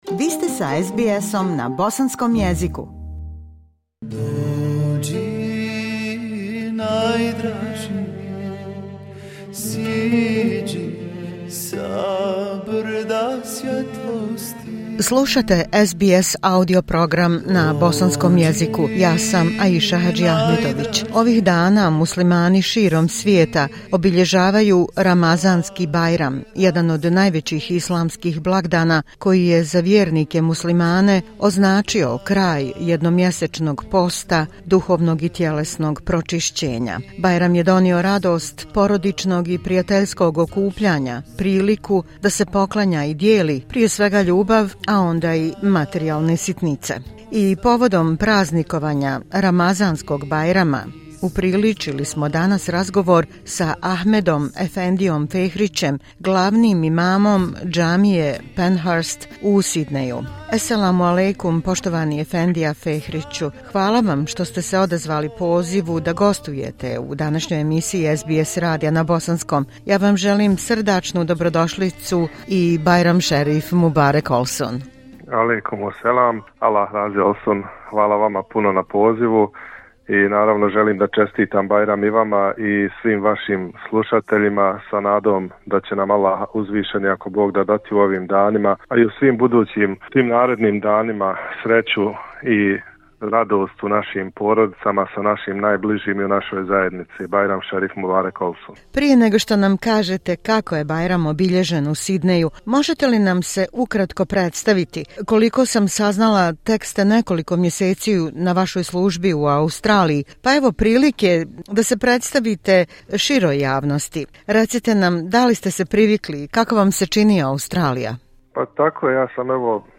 Ovih dana muslimani širom svijeta obilježavaju Ramazanski bajram, jedan od najvećih islamskih blagdana koji je za vjernike muslimane označio kraj jednomjesečnog posta, duhovnog i tjelesnog pročišćenja. Tim povodom upriličili smo razgovor